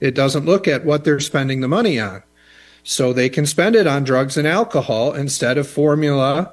Hall says the program has “a lot of money.”